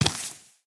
Minecraft Version Minecraft Version snapshot Latest Release | Latest Snapshot snapshot / assets / minecraft / sounds / mob / husk / step4.ogg Compare With Compare With Latest Release | Latest Snapshot
step4.ogg